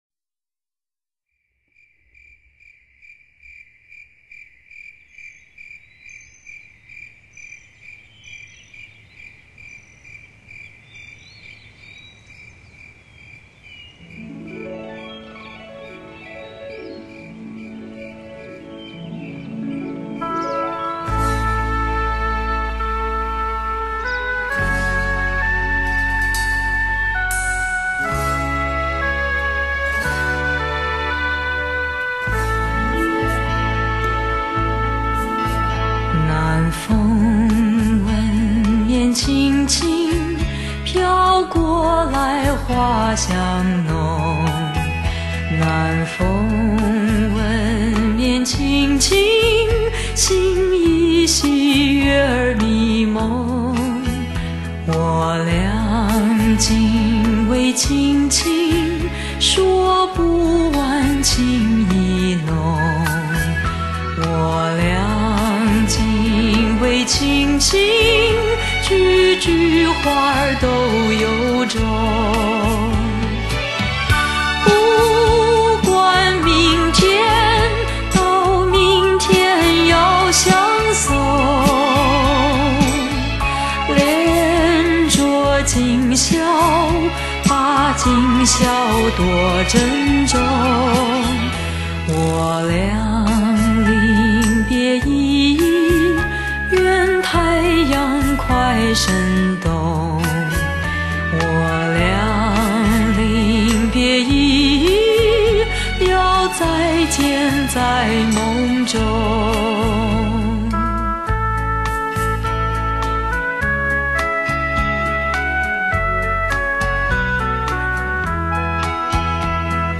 低沉优雅的嗓音，蕴含着成熟女性特有的性感。